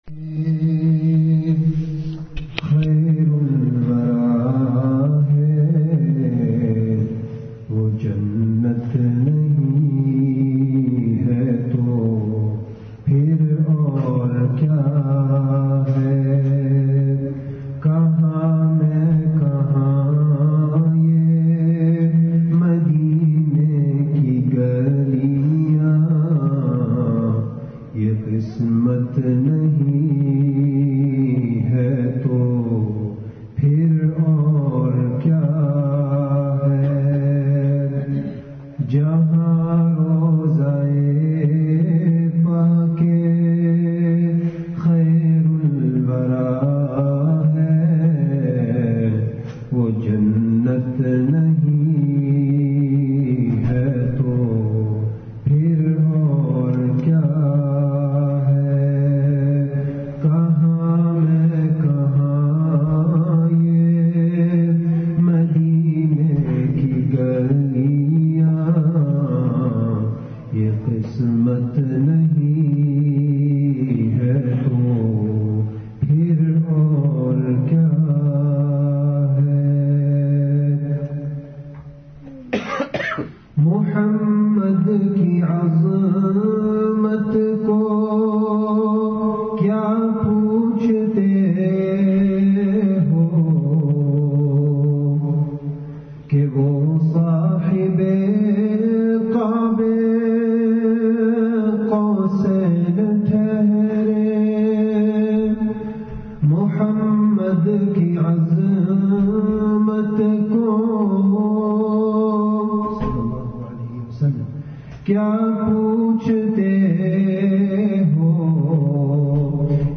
Eid-ul-Adha Bayan 2017-1438